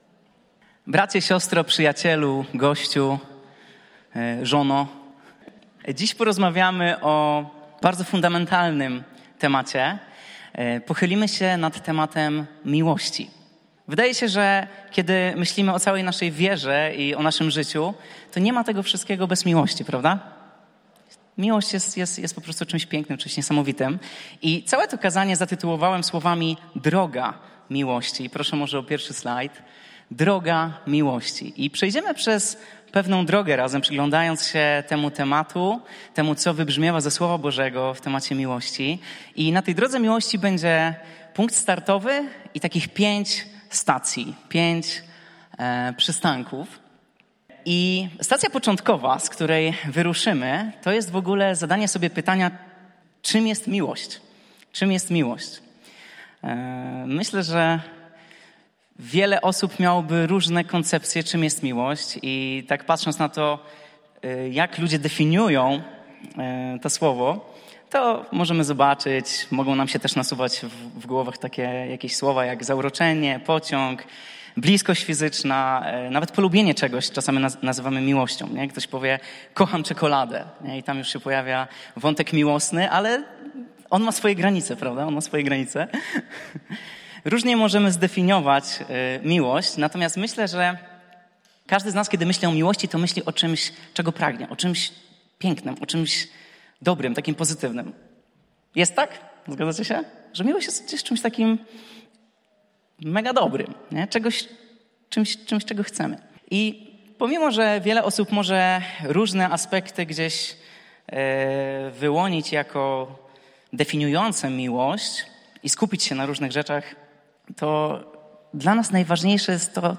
Pytania do przemyślenia po kazaniu: 1) Czy to, co zrobił dla ciebie Jezus, stale cię zachwyca?